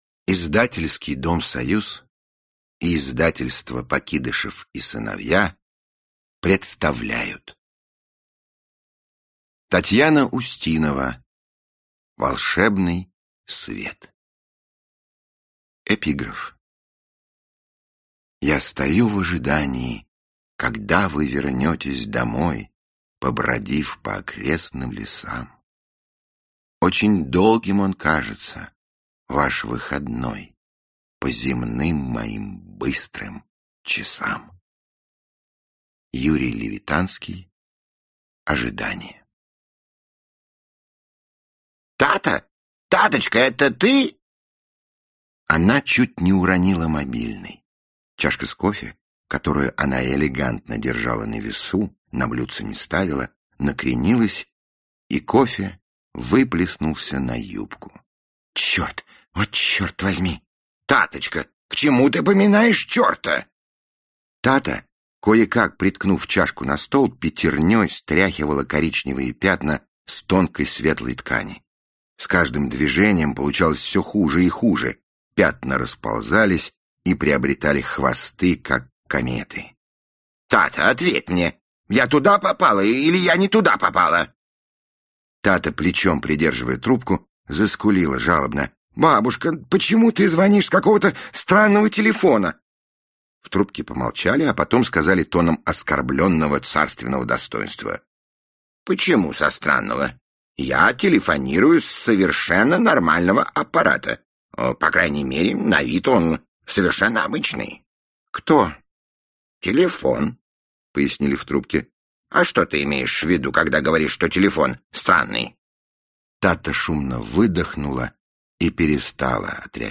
Аудиокнига Волшебный свет | Библиотека аудиокниг
Aудиокнига Волшебный свет Автор Татьяна Устинова Читает аудиокнигу Михаил Горевой.